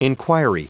Prononciation du mot enquiry en anglais (fichier audio)
Prononciation du mot : enquiry